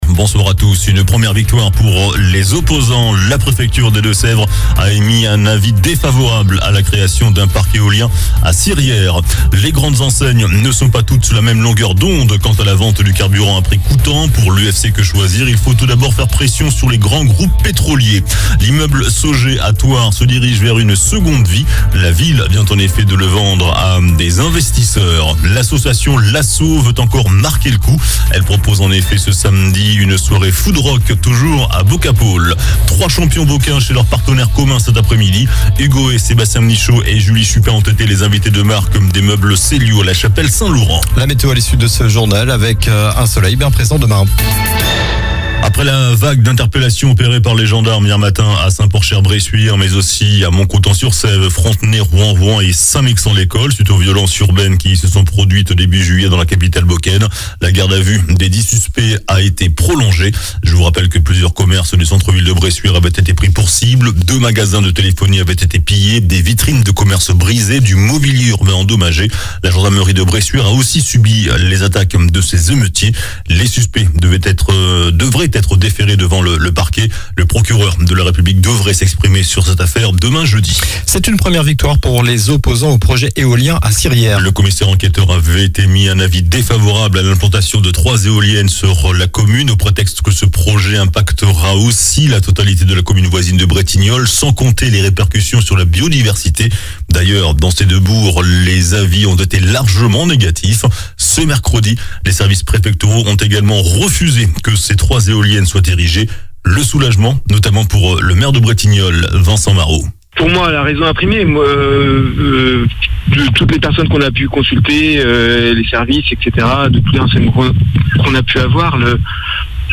JOURNAL DU MERCREDI 27 SEPTEMBRE ( SOIR )